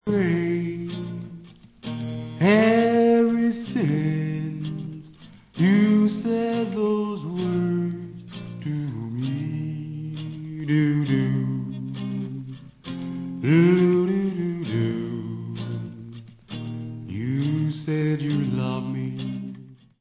The best r&b guitarist in the world!? he did it all!!